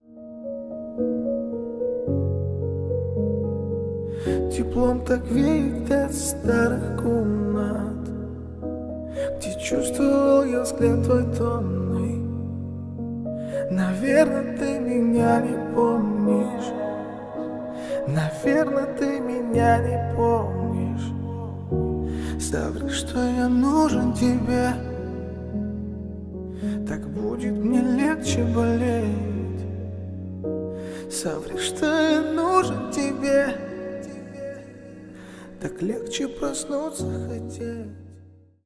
Поп Музыка
спокойные # тихие